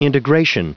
Prononciation du mot integration en anglais (fichier audio)